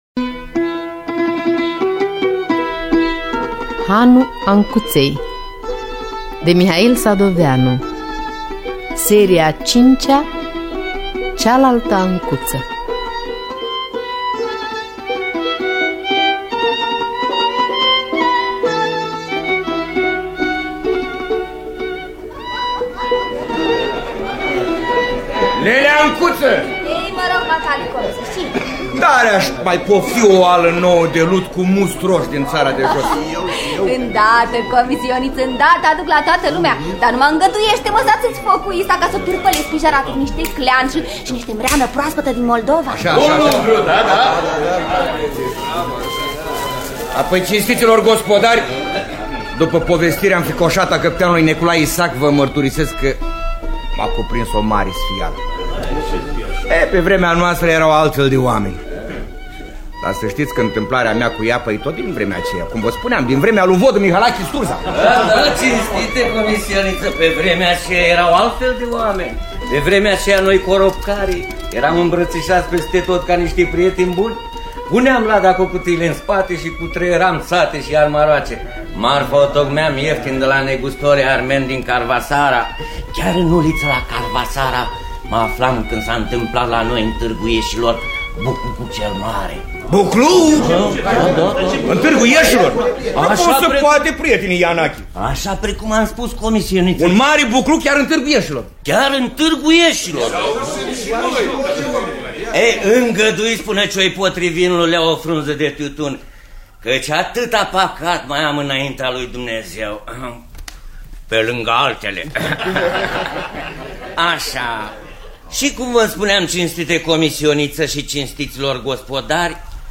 Dramatizarea radiofonică de Valeria Sadoveanu şi Constantin Mitru.